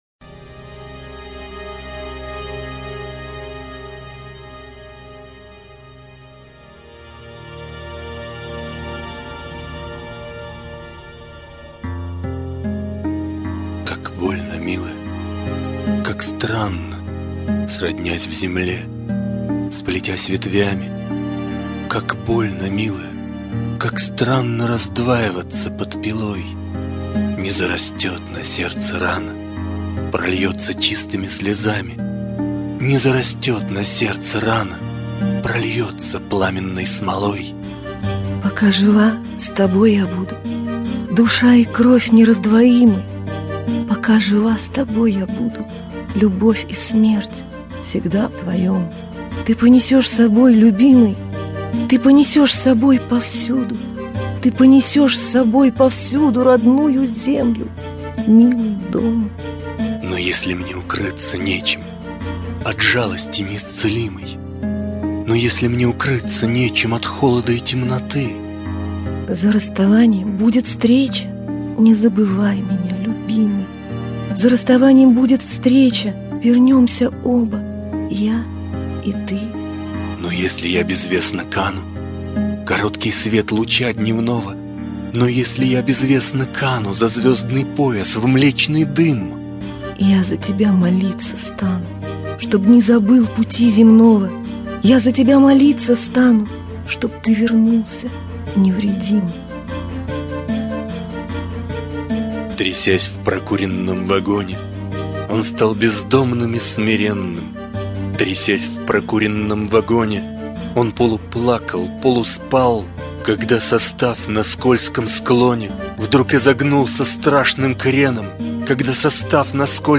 декламация